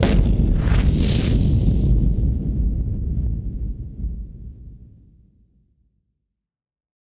BF_HitSplosionB-07.wav